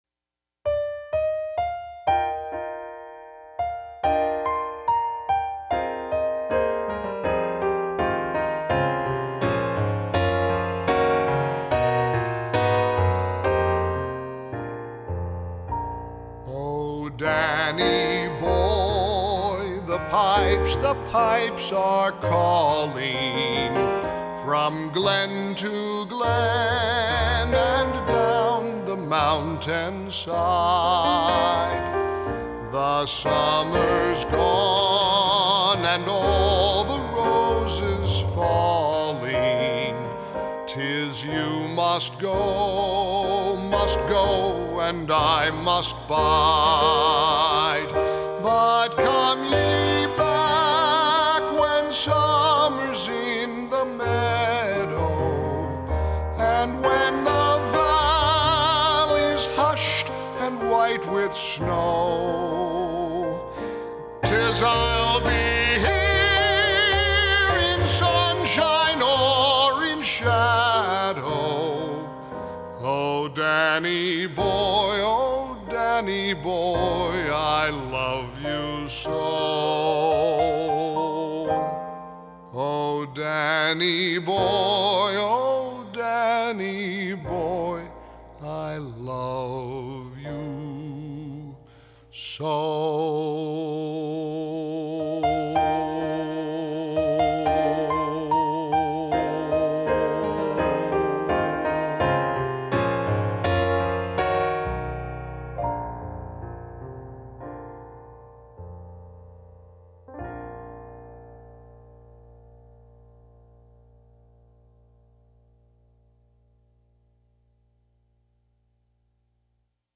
Mike plays his performance singing “Danny Boy” on this St. Patricks Day
Mike-Gallagher-Sings-Danny-Boy.mp3